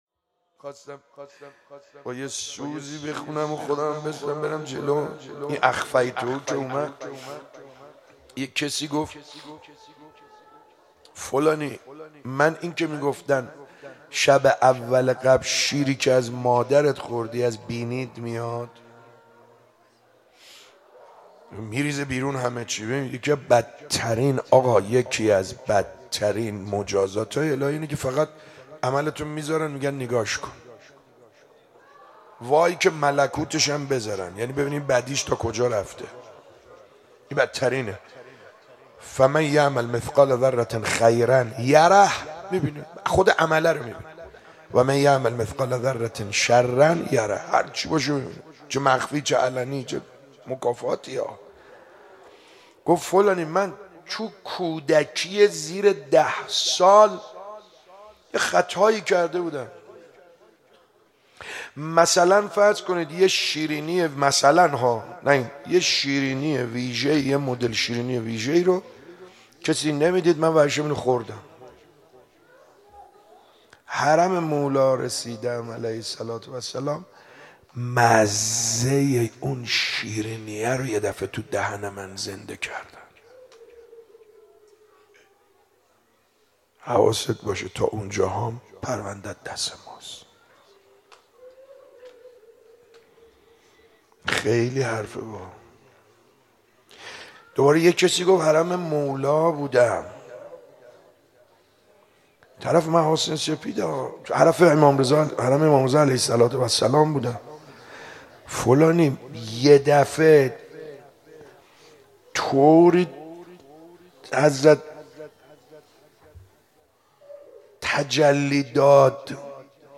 شب چهارم ماه رمضان 95_دعا خوانی